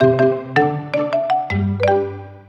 mallet.wav